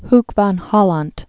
(hk vän hôlänt)